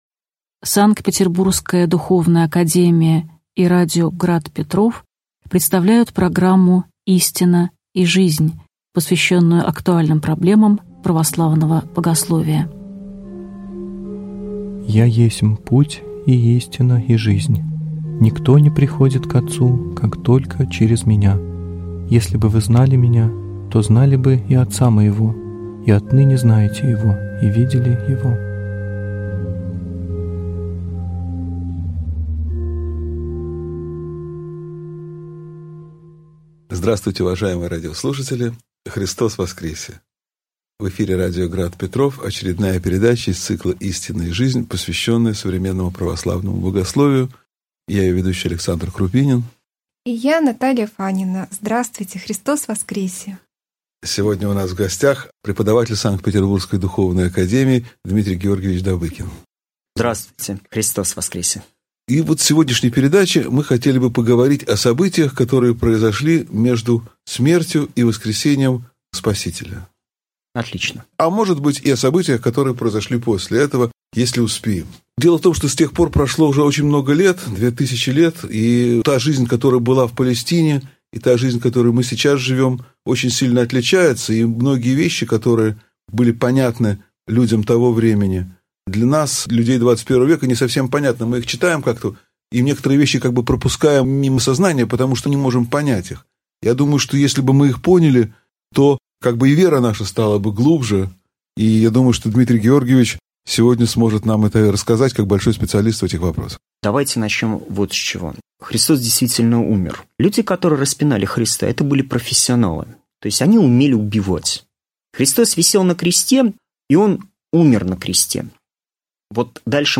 Аудиокнига Воскресение Христово (часть 1) | Библиотека аудиокниг